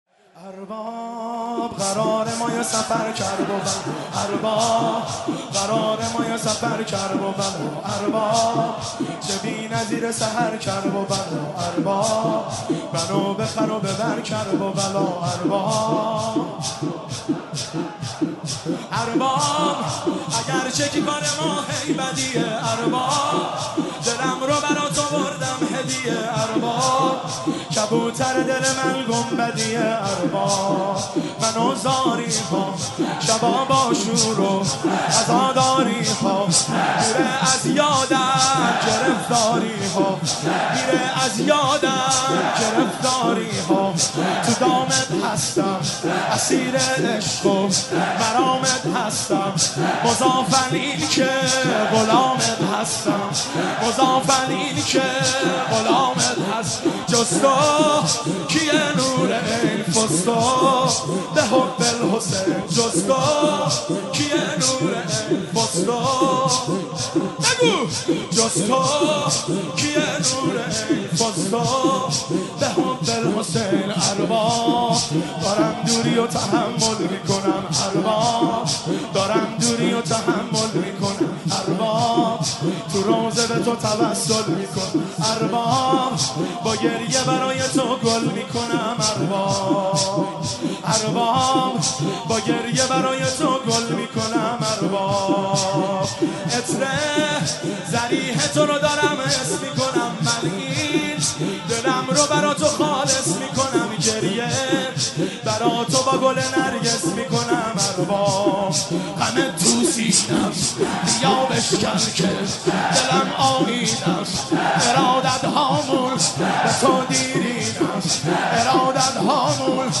مداحی ارباب قرار ما یه سفر کرببلا(شور) شب پنجم محرم 1392 هیئت خادم الرضا(ع) قم
مداحی ارباب قرار ما یه سفر کرببلا(شور)